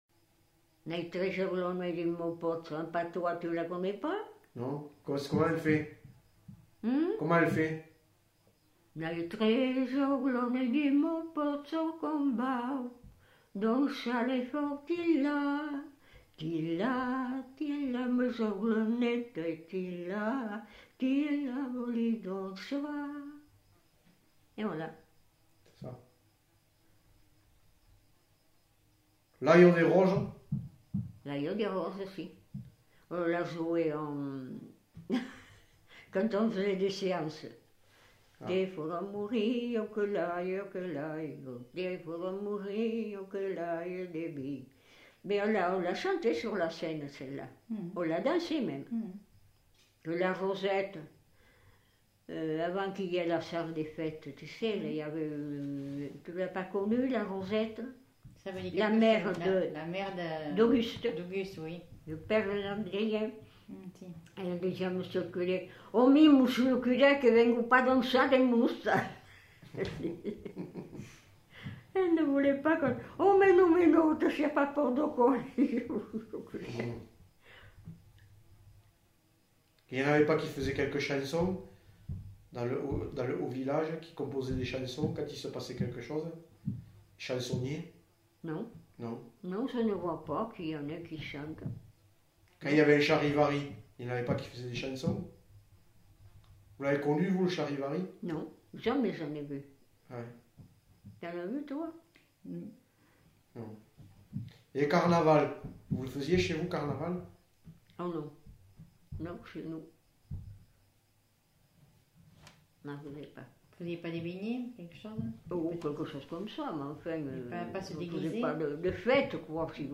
Aire culturelle : Quercy
Genre : chant
Effectif : 1
Type de voix : voix de femme
Production du son : chanté
Danse : bourrée